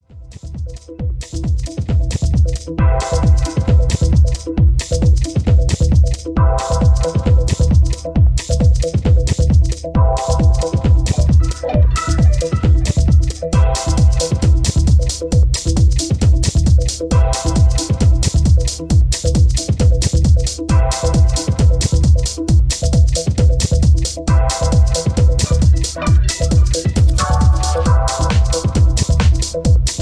Positive dynamic techno house track